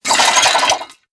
CHQ_VP_headshake.ogg